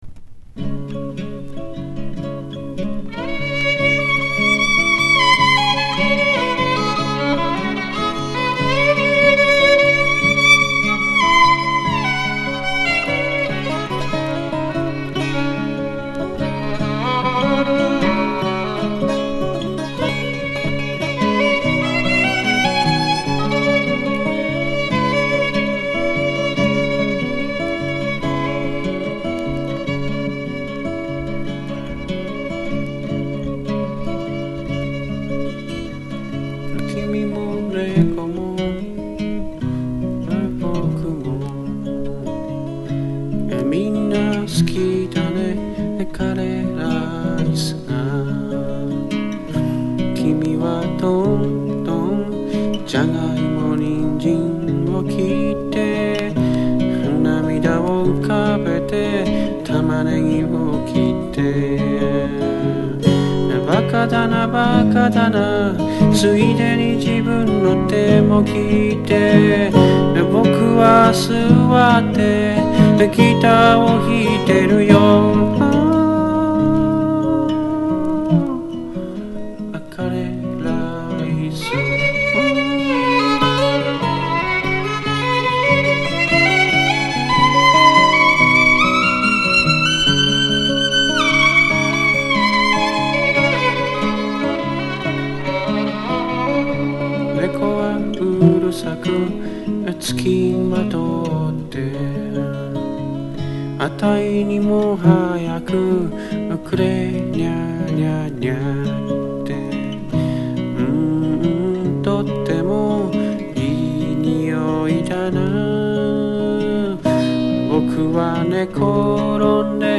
SSW / FOLK
音作りも東洋的なバイオリンメロディー、本当なら胡弓とかでしょうが、そんなオリエンタルな雰囲気でアシッドに歌ってます。